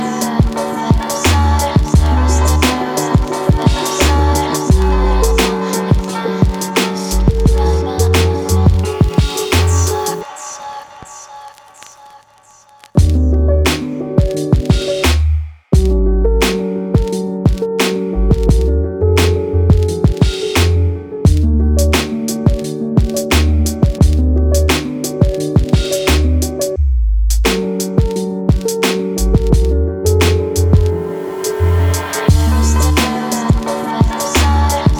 # Easy Listening